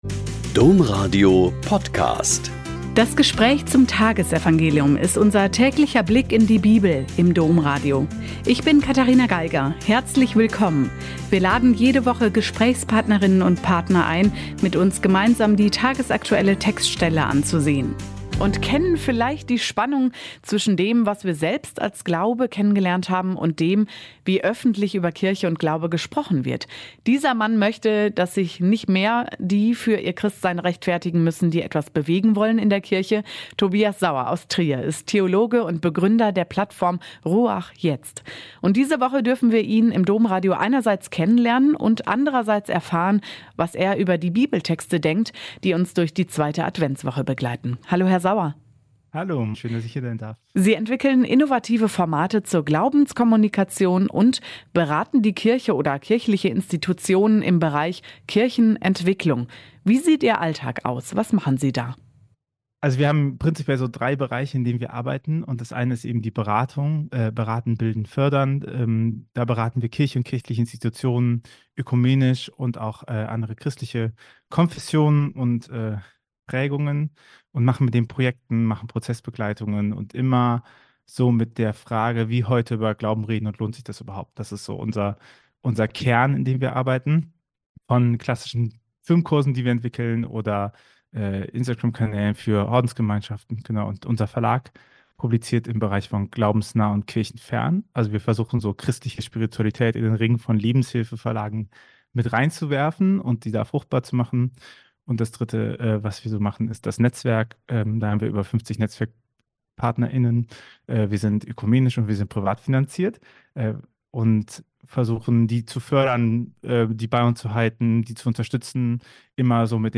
Lk 1,26-38 - Gespräch